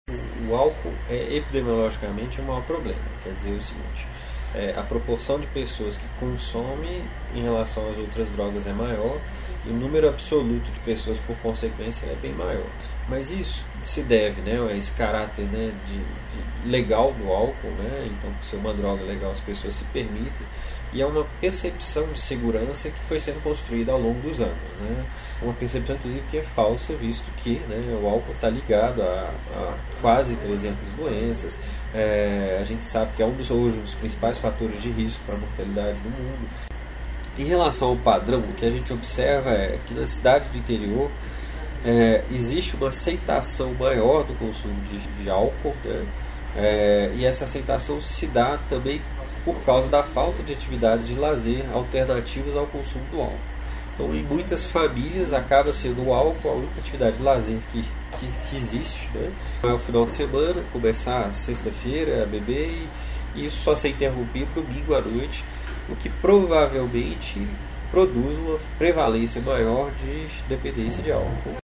Especialista fala sobre a realidade atual do consumo de drogas lícitas e ilícitas no interior.